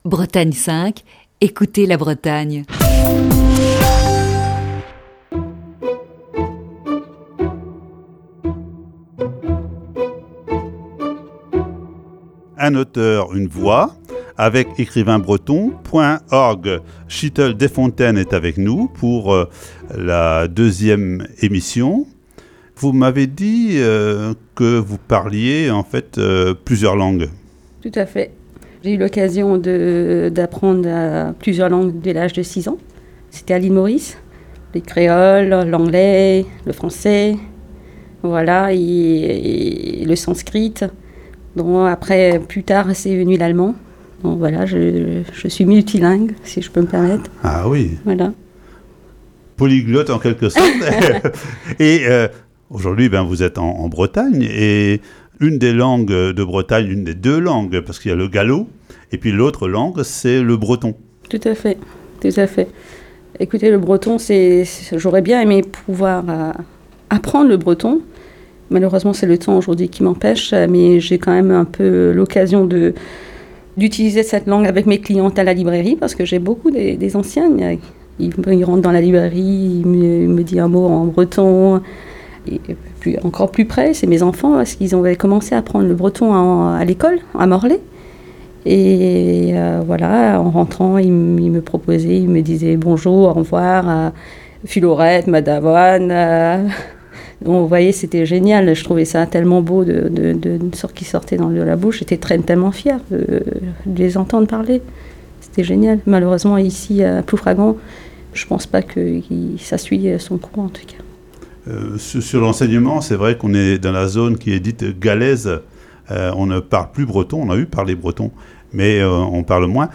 Ce matin, deuxième partie de cet entretien diffusé le 4 février 2020.